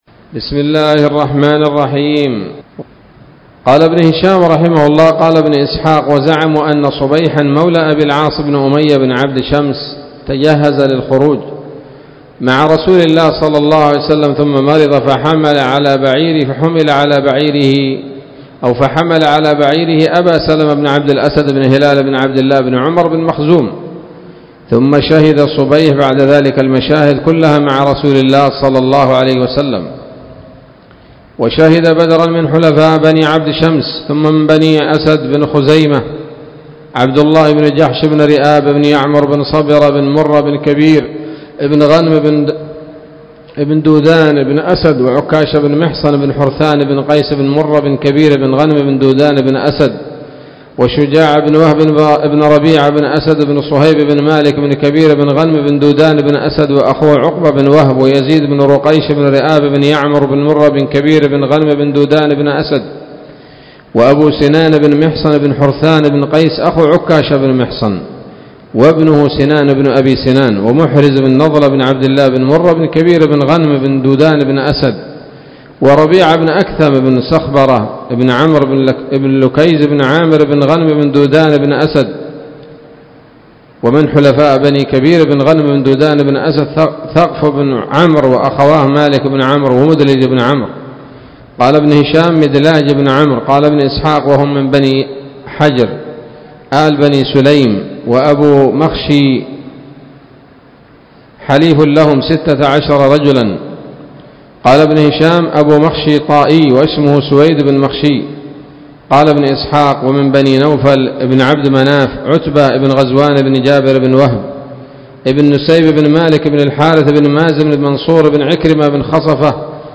الدرس الثالث والثلاثون بعد المائة من التعليق على كتاب السيرة النبوية لابن هشام